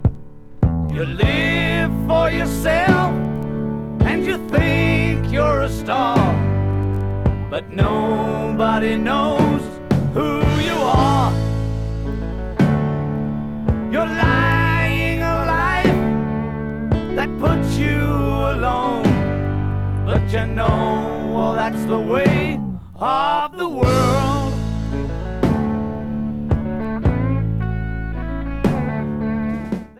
Remixed version